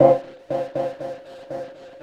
Echo Noise Burst.wav